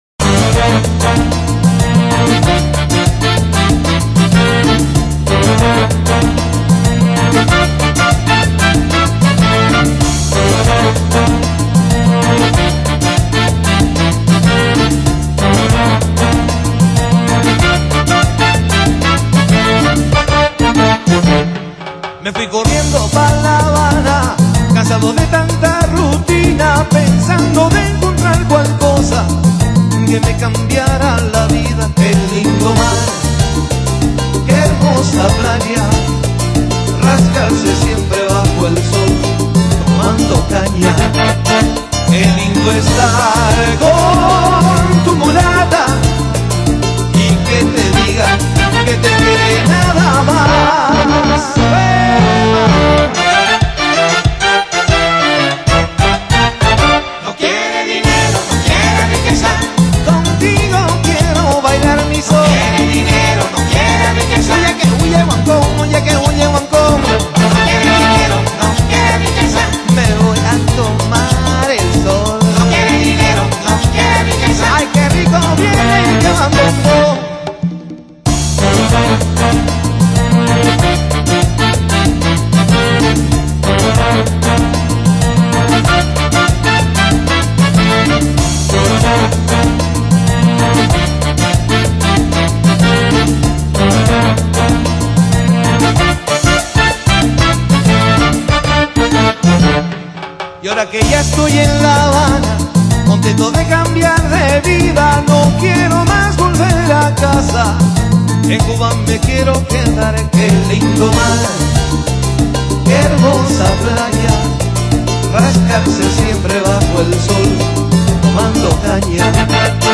New Age
门金曲，旋律性强而富有动感，散发着无穷的时尚气息。